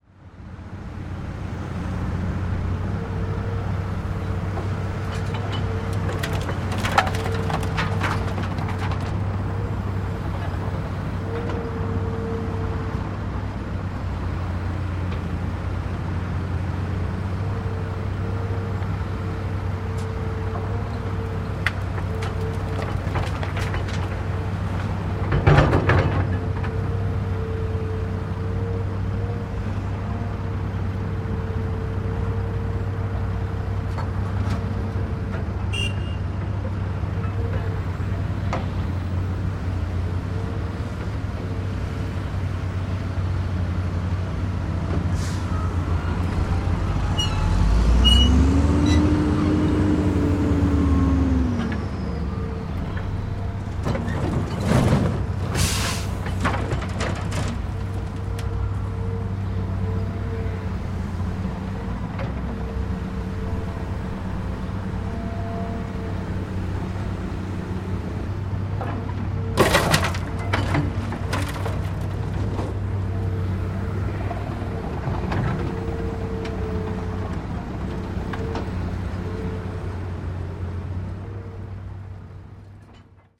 Шум работы автокрана